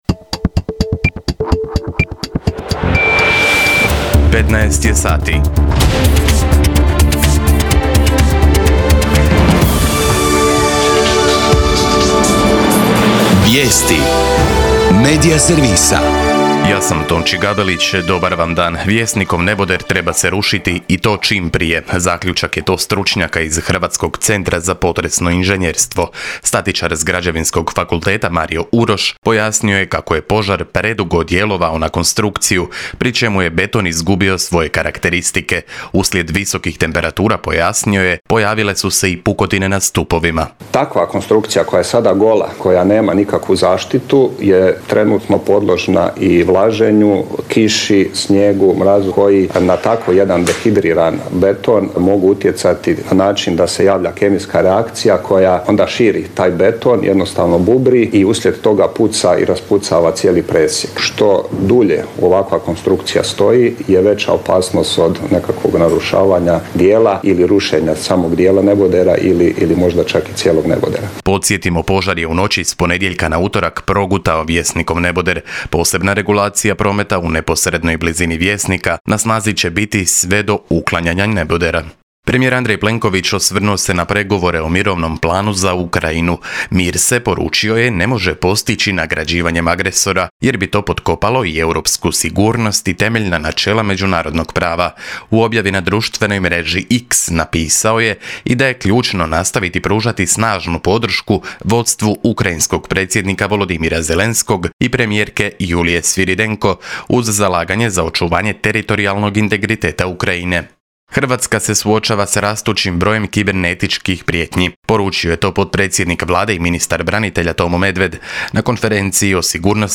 VIJESTI U 15